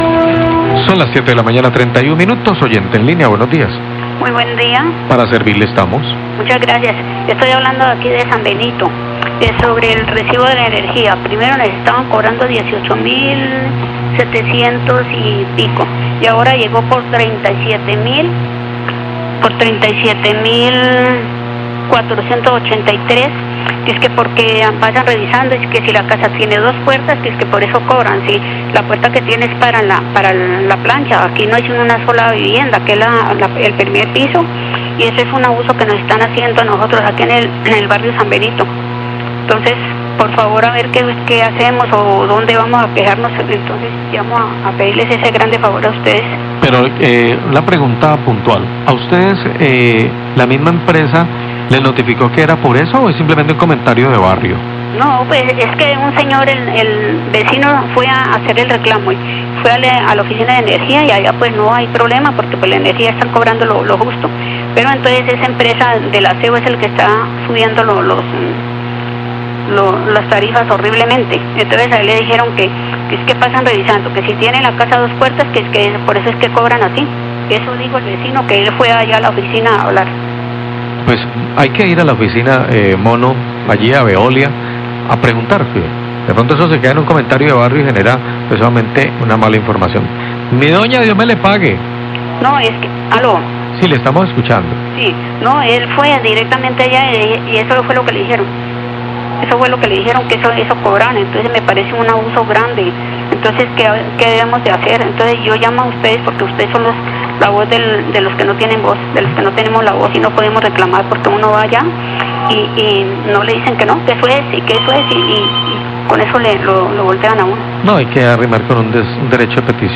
Oyente se queja por el incremento en el servicio de aseo que se cobra en el recibo de energía, Robles, 740am
Radio